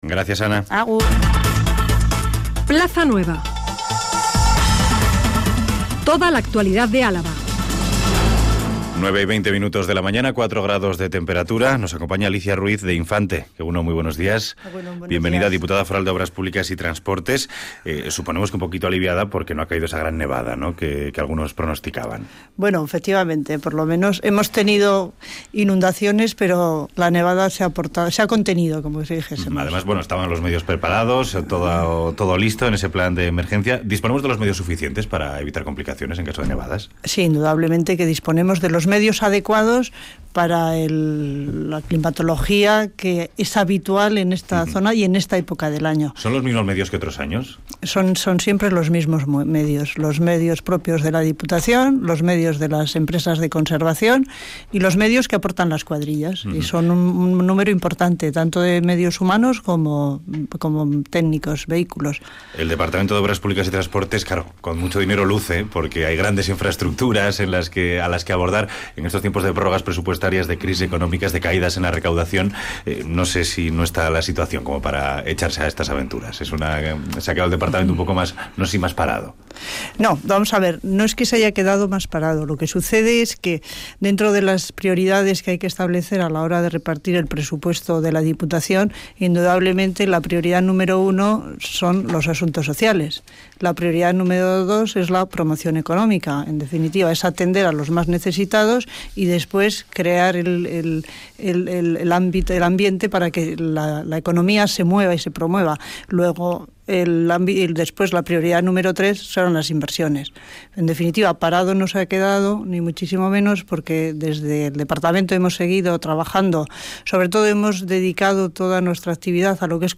Entrevista a Alicia Ruiz de Infante, diputada foral de Obras Públicas